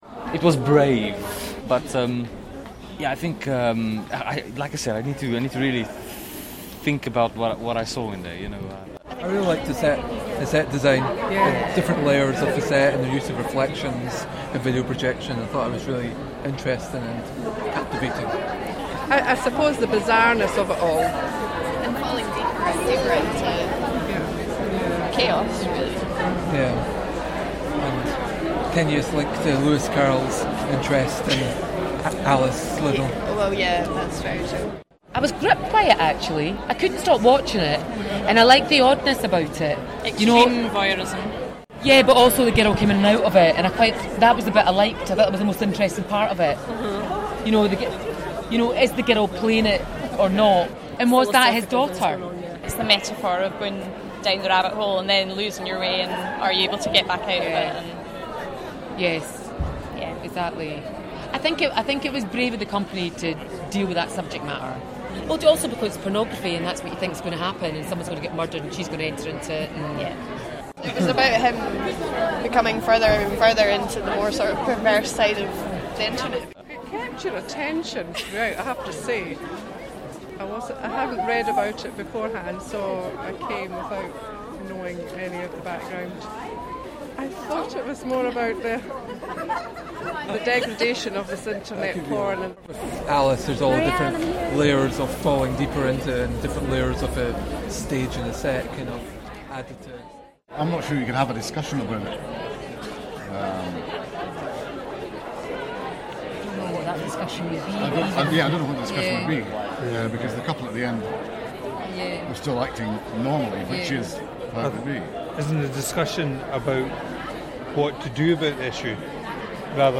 Audience reaction to Wonderland at the Royal Lyceum Theatre by edintfest's posts